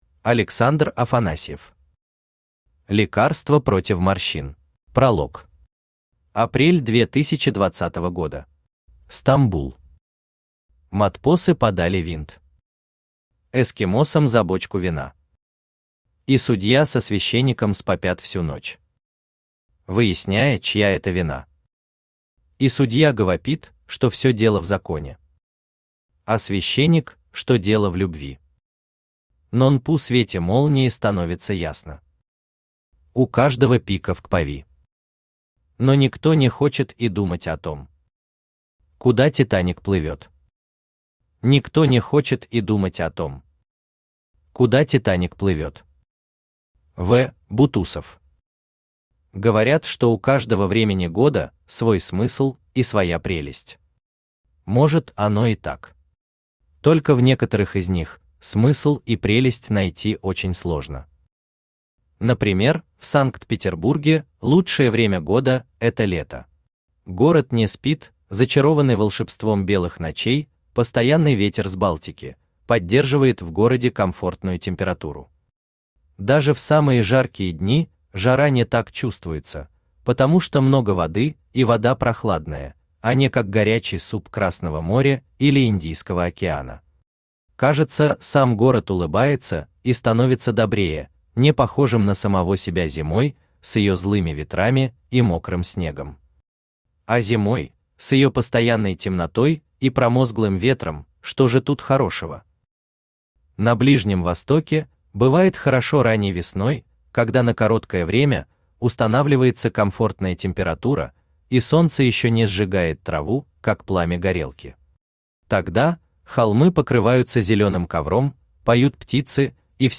Aудиокнига Лекарство против морщин Автор Александр Афанасьев Читает аудиокнигу Авточтец ЛитРес.